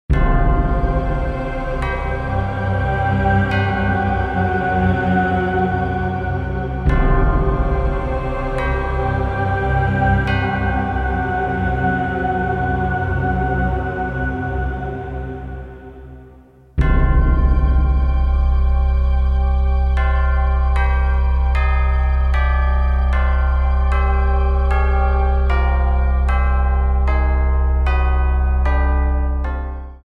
The ULTIMATE haunted house CD!